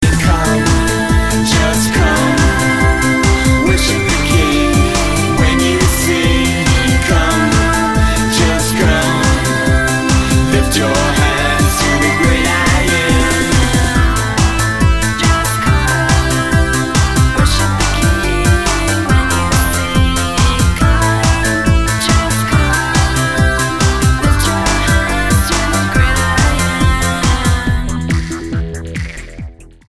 STYLE: Dance/Electronic
synth-pop trio